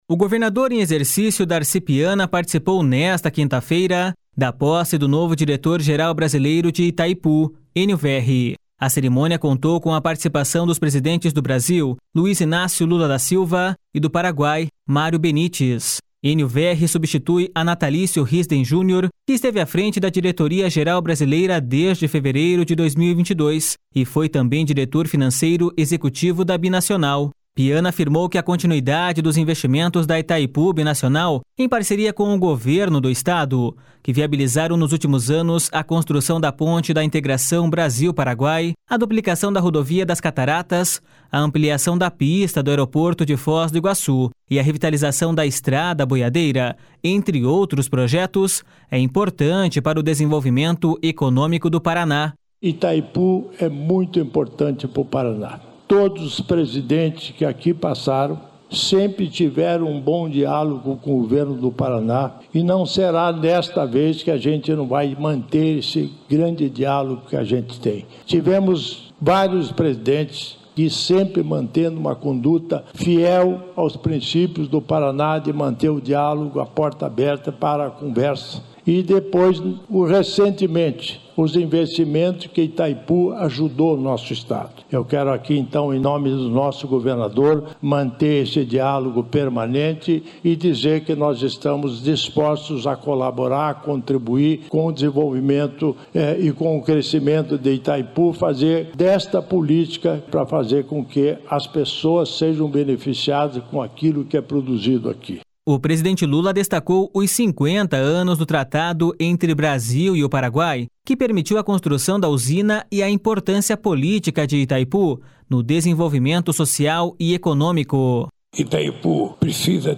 Piana afirmou que a continuidade dos investimentos da Itaipu Binacional em parceria com o Governo do Estado, que viabilizaram nos últimos anos a construção da Ponte da Integração Brasil-Paraguai, a duplicação da Rodovia das Cataratas, a ampliação da pista do Aeroporto de Foz do Iguaçu e a revitalização da Estrada Boiadeira, entre outros projetos, é importante para o desenvolvimento econômico do Paraná.// SONORA DARCI PIANA.//
O presidente Lula destacou os 50 anos do tratado entre o Brasil e o Paraguai que permitiu a construção da usina e a importância política de Itaipu no desenvolvimento social e econômico.// SONORA LUIZ INÁCIO LULA DA SILVA.//
O novo diretor-geral brasileiro disse que deve fortalecer o papel estratégico de Itaipu no desenvolvimento nacional e do Estado.// SONORA ENIO VERRI.//